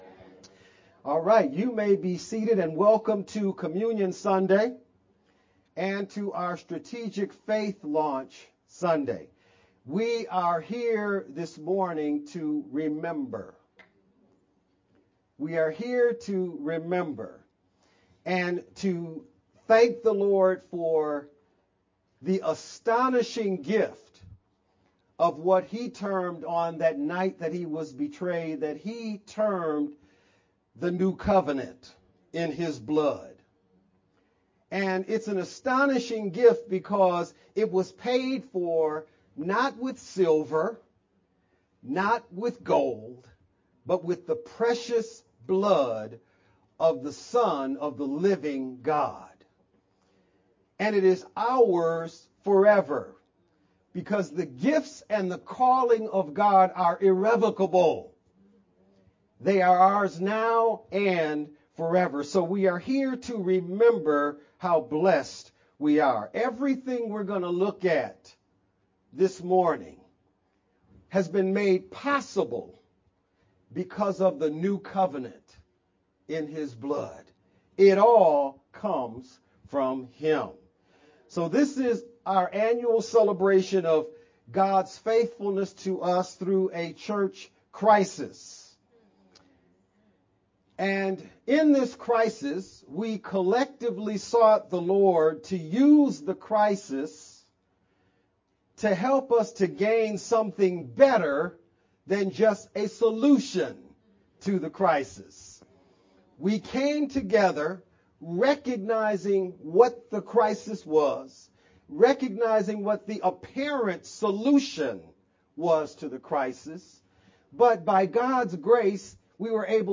VBCC-Sermon-only-edited-6-23-24_Converted-CD.mp3